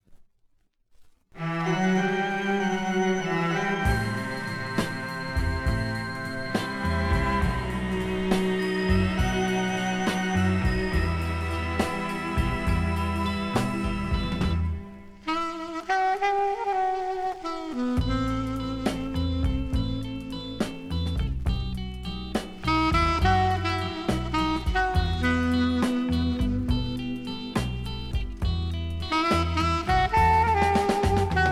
• Качество: 320, Stereo
без слов
красивая мелодия
инструментальные
Саксофон
романтичные
80-е